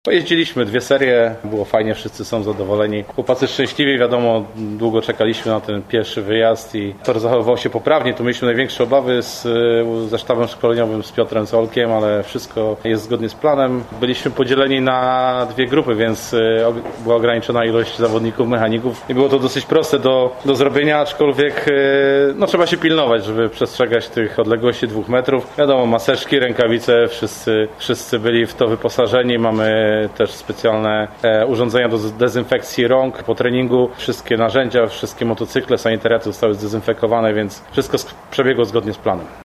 Dzięki uprzejmości zielonogórskiego klubu mamy wypowiedzi przedstawiciela sztabu szkoleniowego oraz zawodników Falubazu.